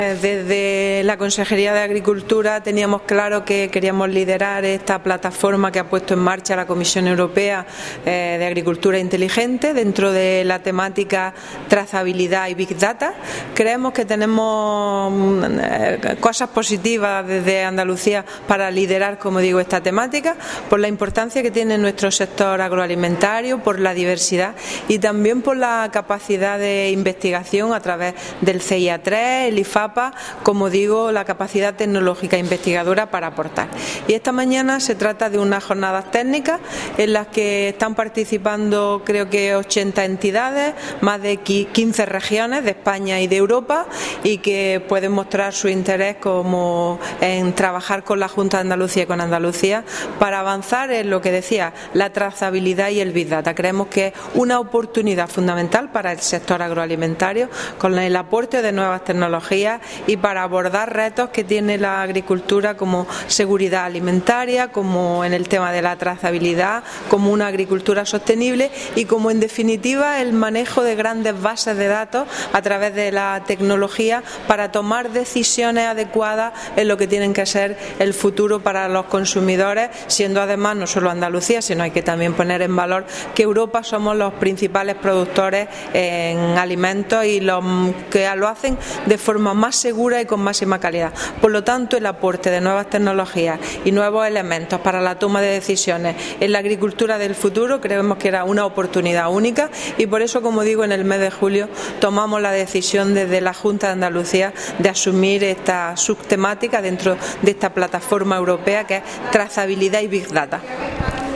Jornada 'Trazabilidad y Big Data'
Declaraciones consejera jornada Big Data